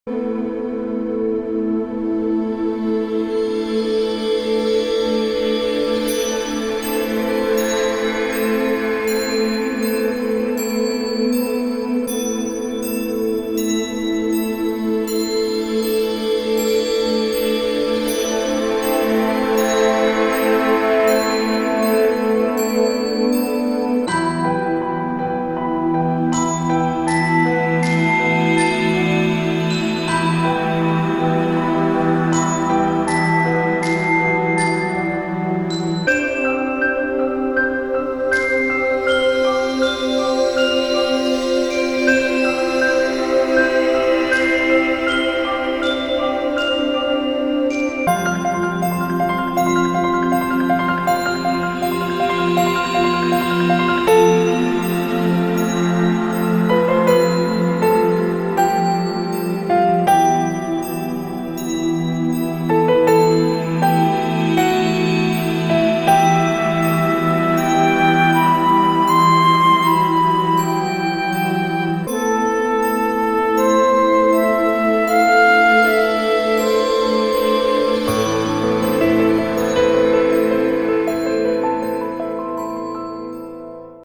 ΟΡΧΗΣΤΡΙΚΑ